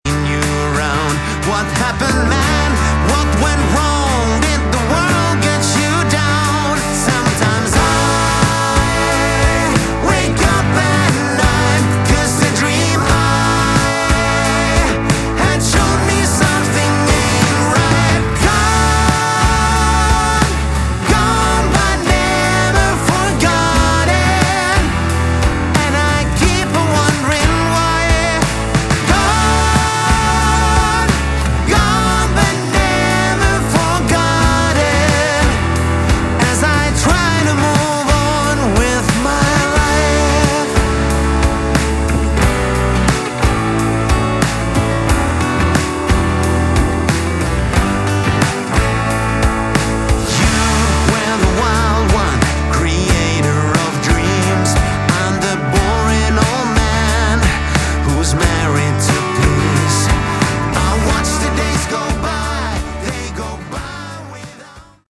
Category: Melodic Rock
vocals
guitars
drums
bass